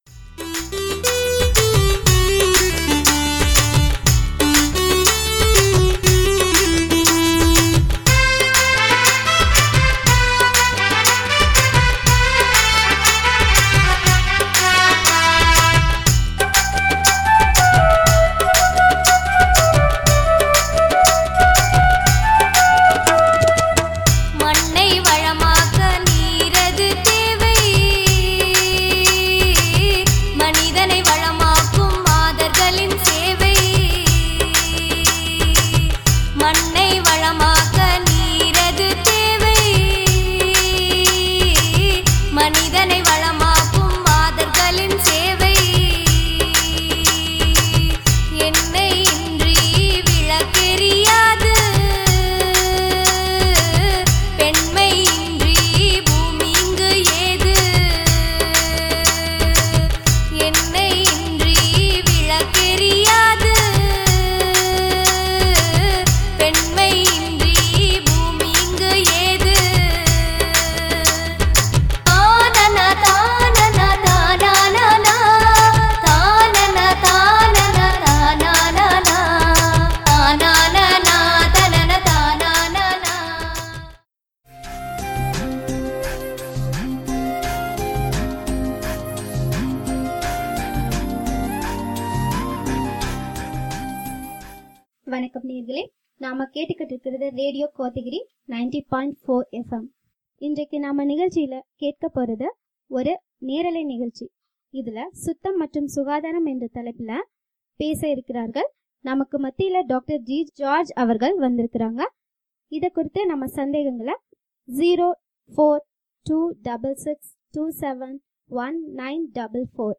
23-3-17 Phone in Live_General health EP15.mp3
Part of Phone in Live_General Health EP15